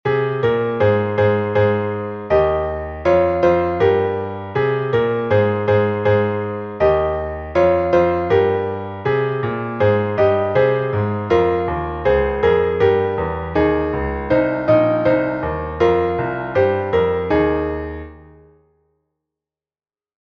Chanson traditionnelle